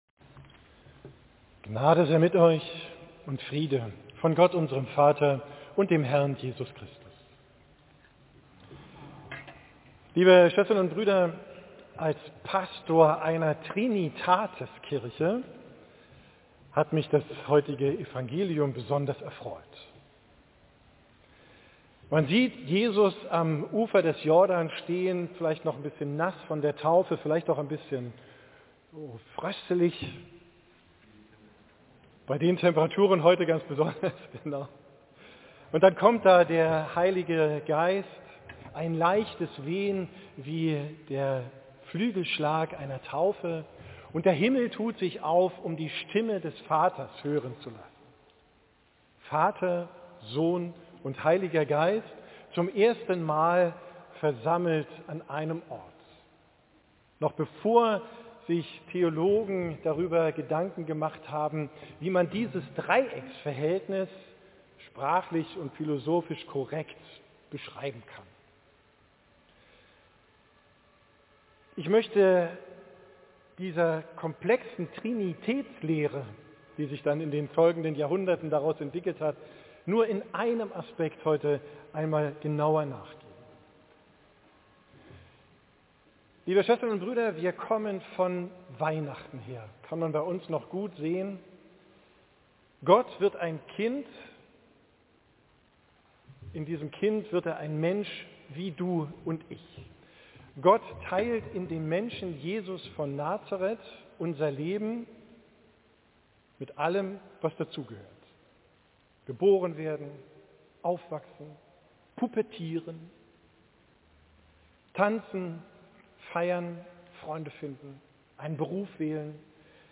Predigt am 1.